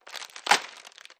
Chip Bag | Sneak On The Lot
Potato Chip Bag, Opening Slowly